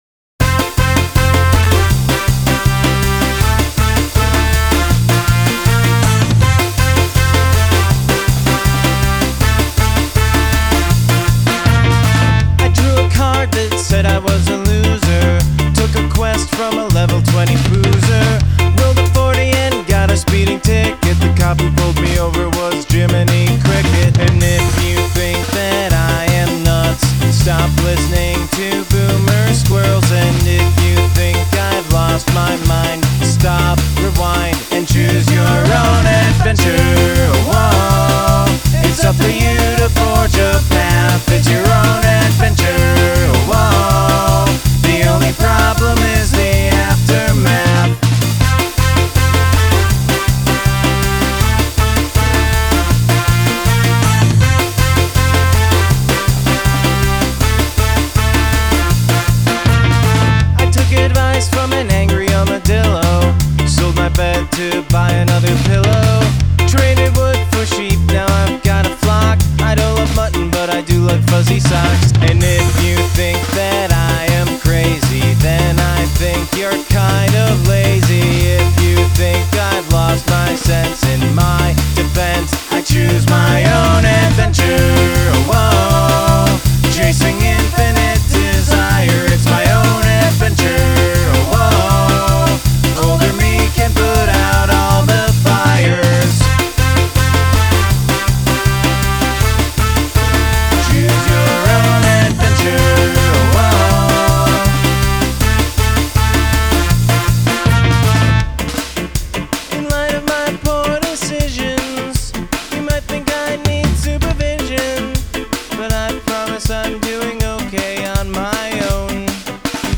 Ska or Glitch (not both)